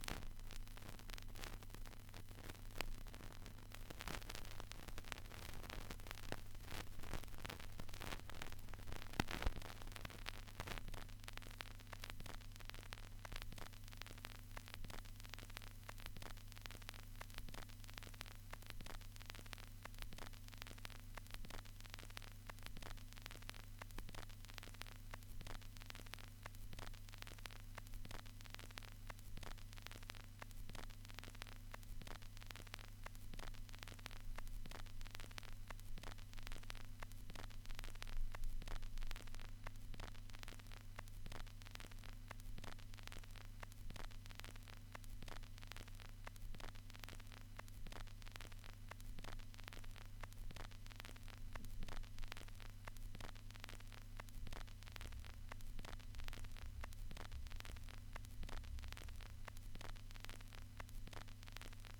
Vinyl snap, crackle and pop redux
crackle gramophone lp noise phonograph record scratch scratched sound effect free sound royalty free Sound Effects